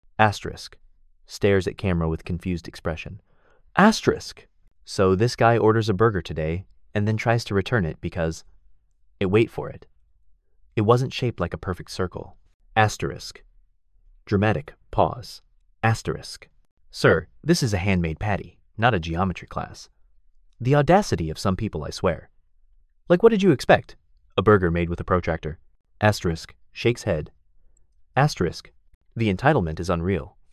Friendly_Person